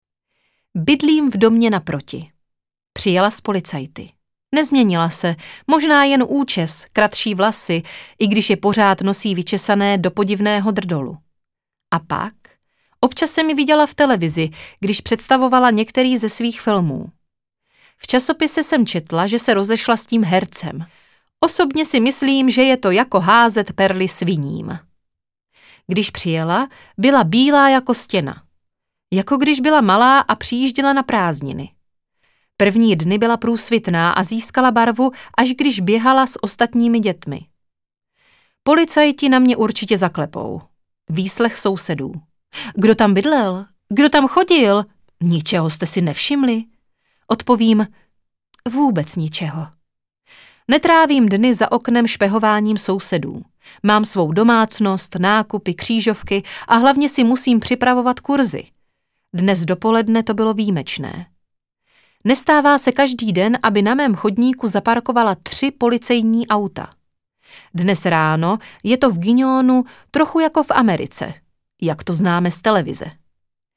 Teta audiokniha
Ukázka z knihy